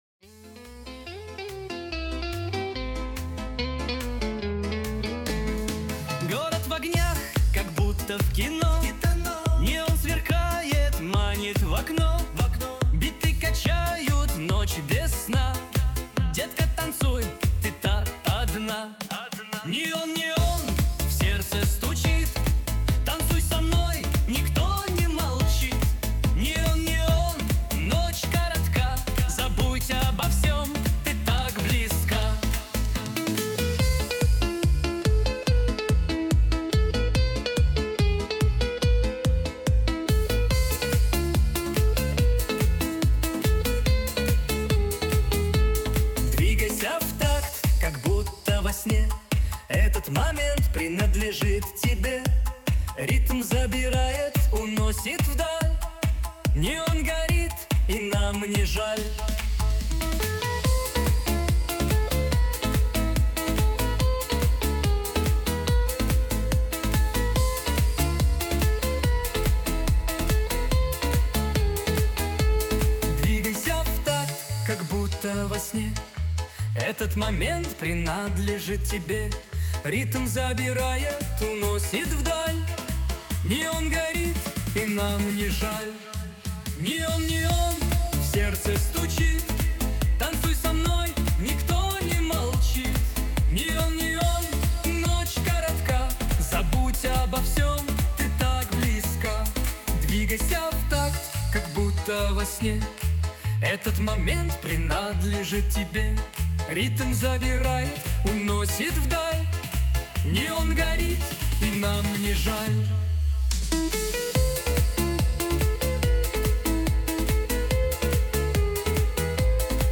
Жанр: Hip Hop